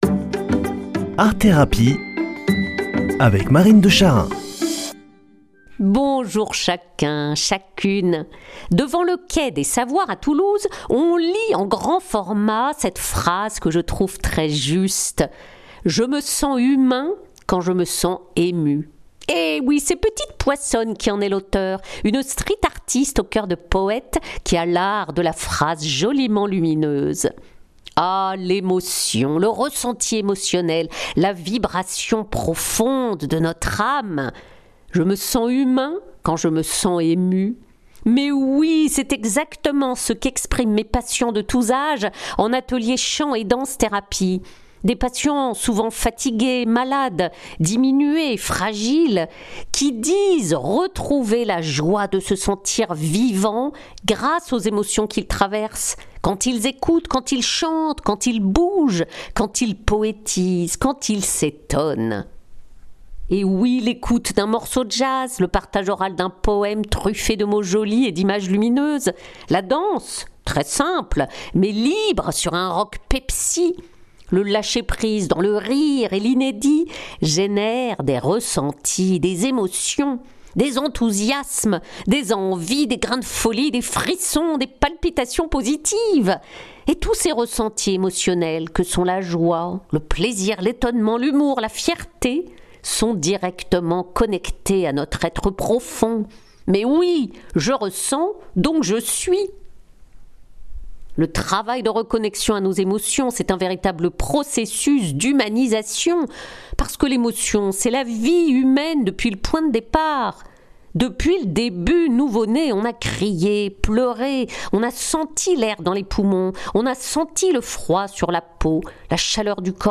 Une émission présentée par